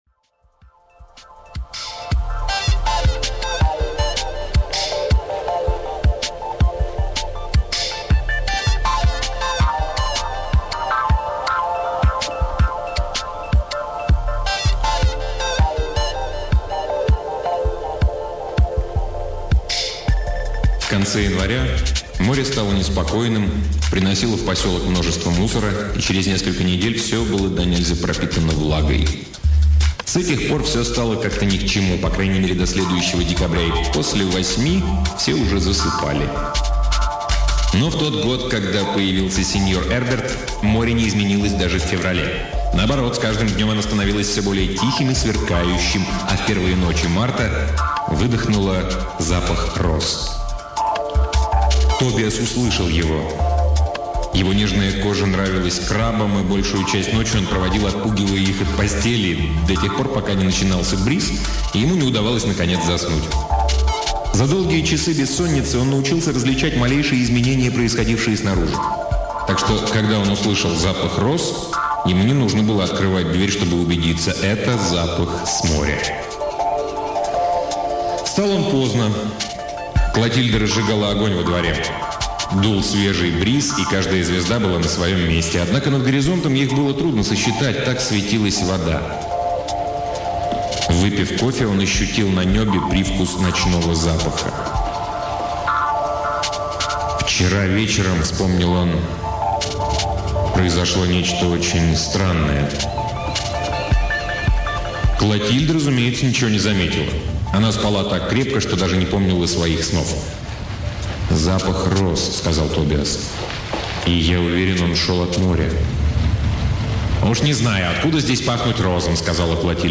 Аудиокнига Габриэль Гарсиа Маркес — Море исчезающих времен